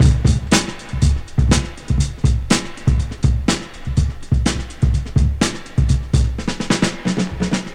• 122 Bpm Drum Groove F Key.wav
Free drum groove - kick tuned to the F note. Loudest frequency: 1061Hz
122-bpm-drum-groove-f-key-UmA.wav